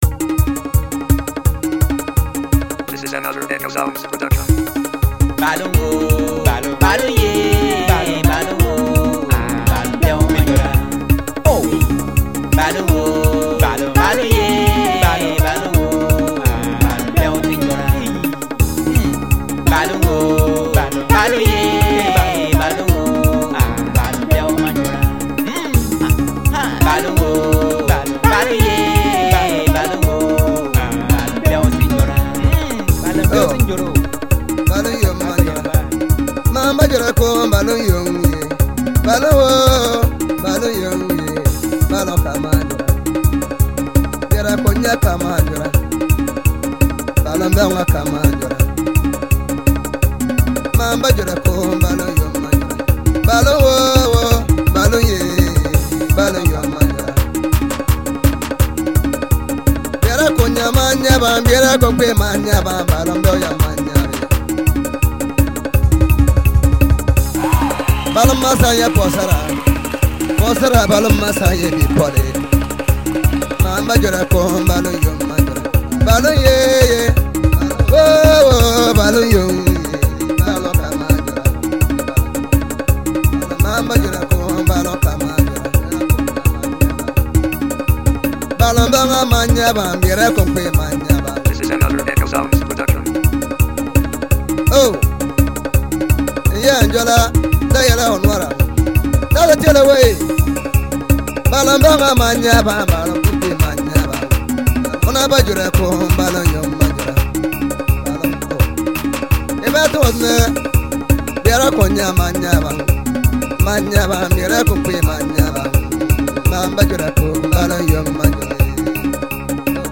Highlife
educative song